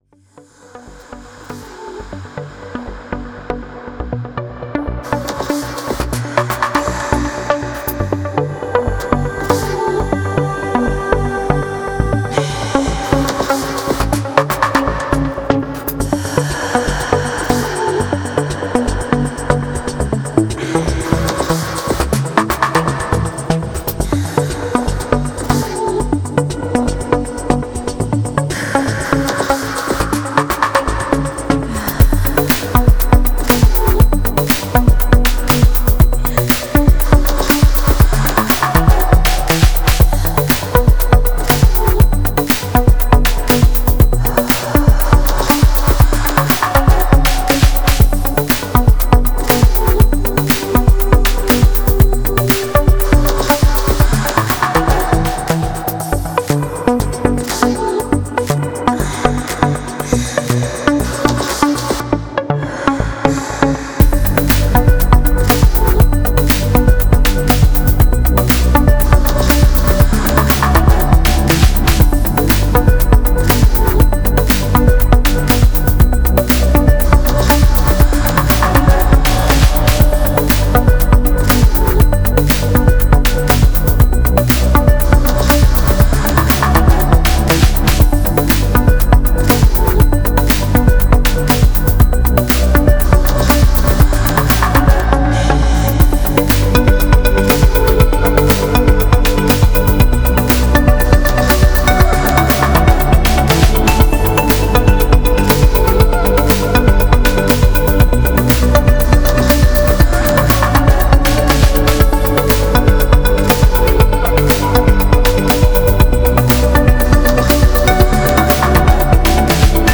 Стиль: Ambient/Downtempo / Electronica